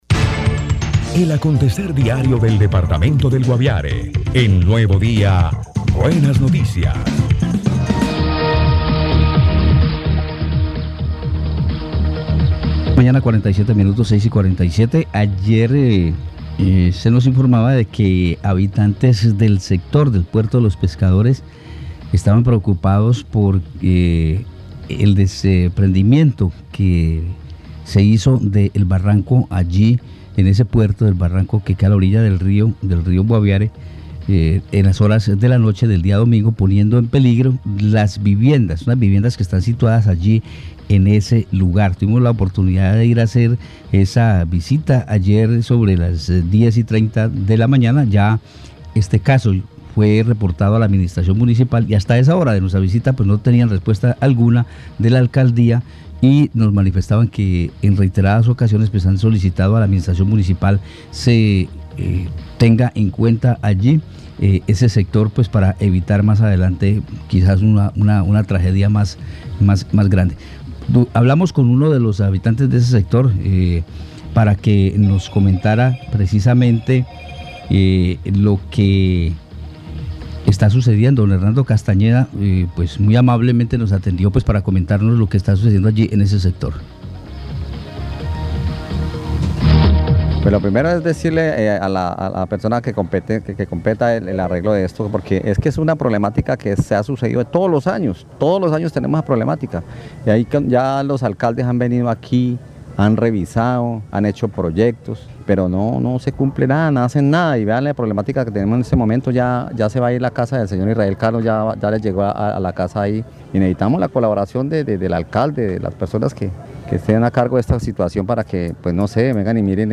habitante del sector.